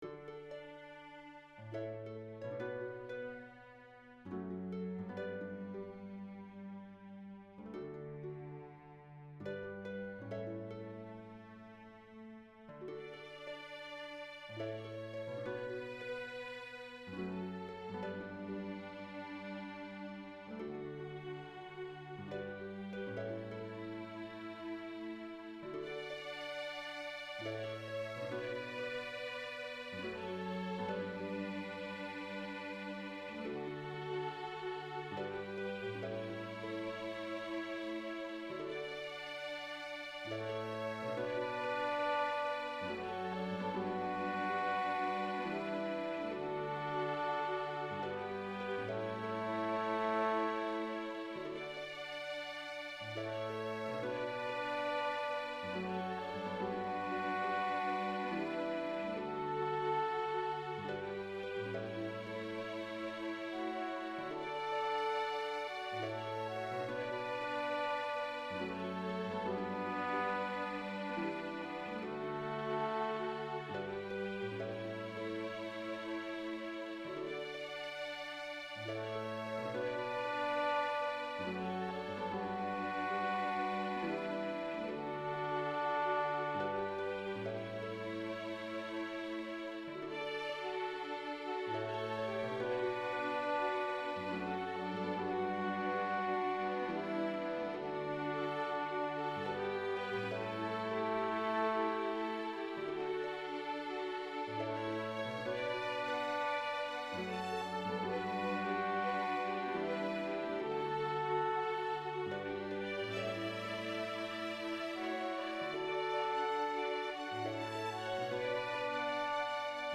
Choir Unison, String Quartet
Voicing/Instrumentation: Choir Unison , String Quartet We also have other 49 arrangements of " Amazing Grace ".